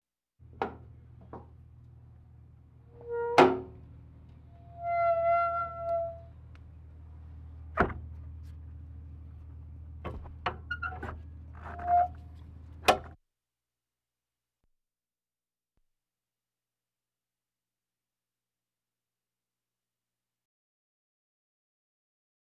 transport
Tank M60 Passenger Latch Open Close